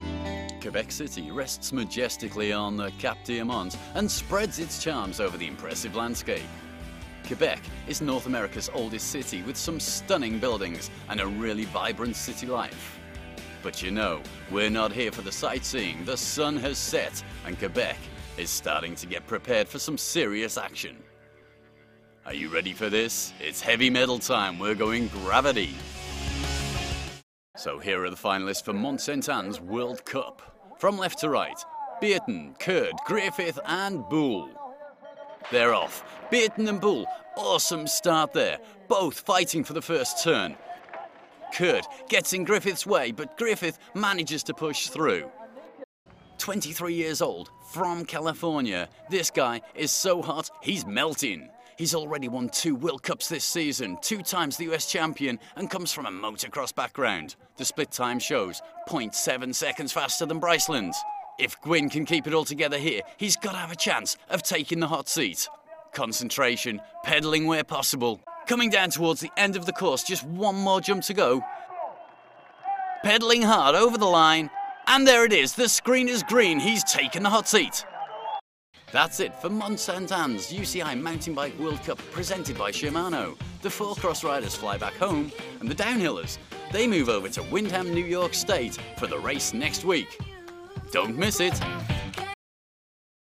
A seasoned, full, deep, mellifluous Britsh English gentleman.
Sports Commentator UCI DH MTB, Action Sports, Highlights, Presenter.
World English, British, UK, Mid-Atlantic
Sports_Commentator_UCI_DH_2011.mp3